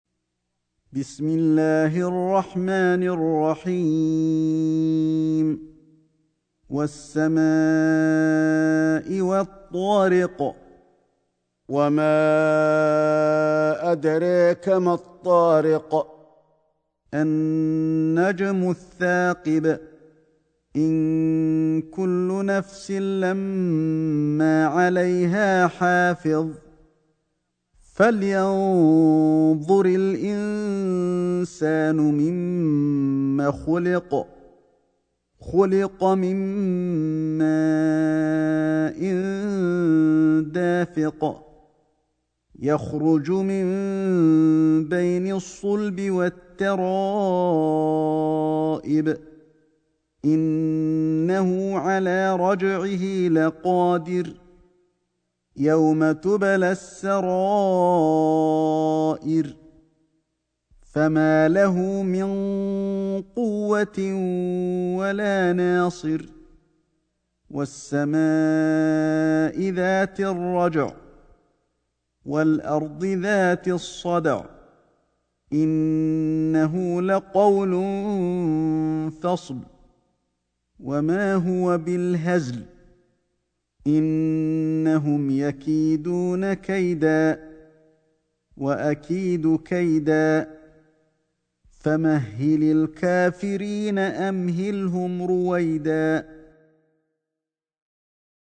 سورة الطارق > مصحف الشيخ علي الحذيفي ( رواية شعبة عن عاصم ) > المصحف - تلاوات الحرمين